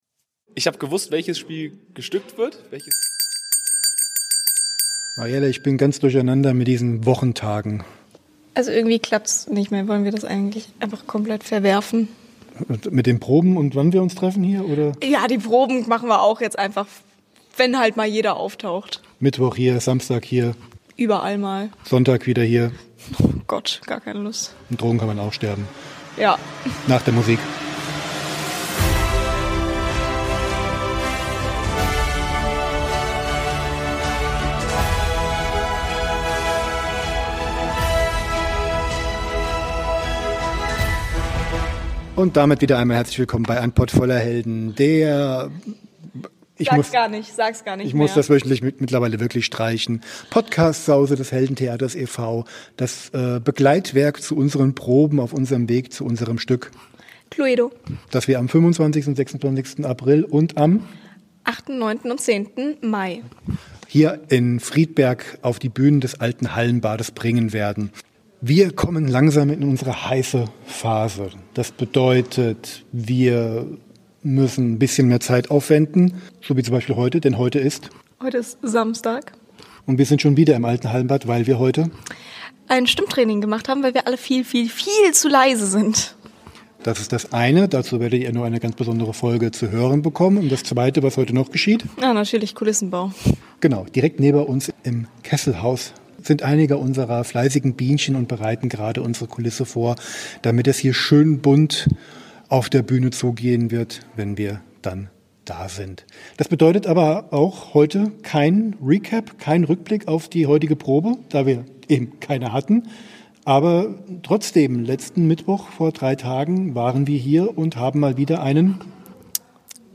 2 Interviews zum Preis von einem.